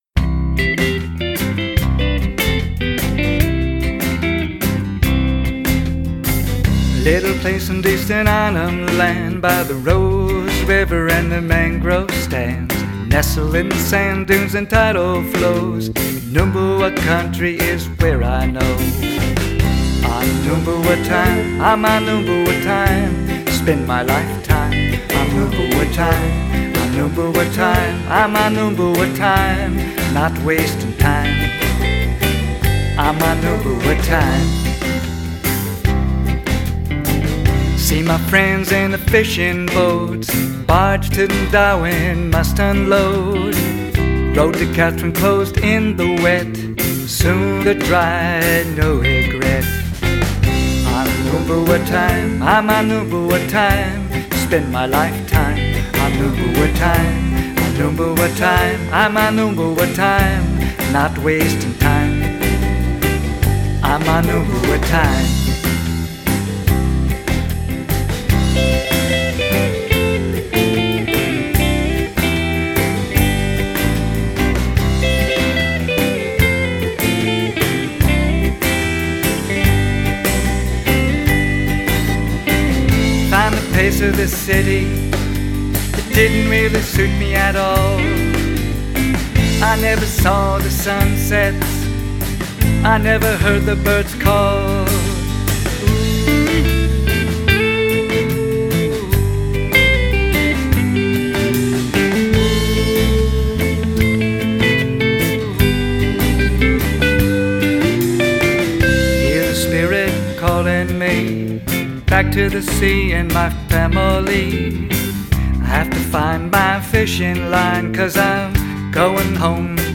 Vocals, Acoustic Guitar, Ukulele
Drums
Bass Guitar